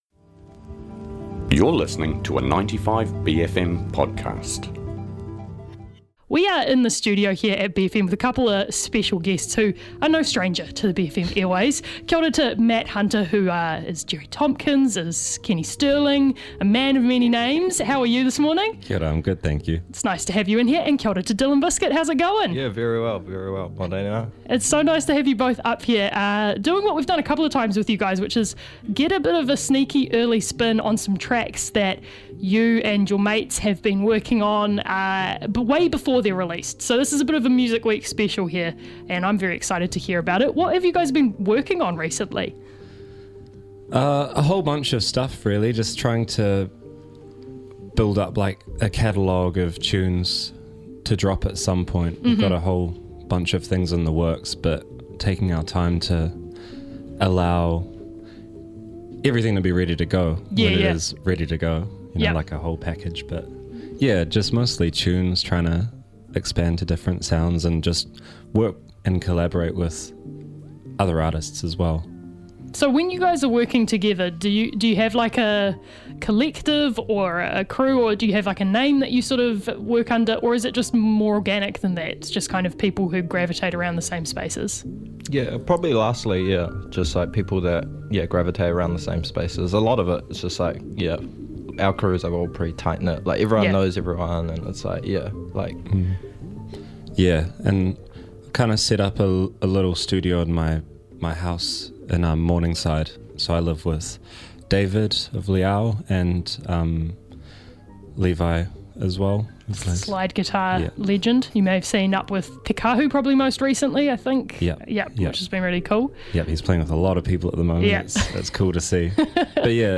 drop by the studio to play some very fresh unreleased tracks.